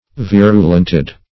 virulented - definition of virulented - synonyms, pronunciation, spelling from Free Dictionary
Virulented \Vir"u*lent*ed\, a. Made virulent; poisoned.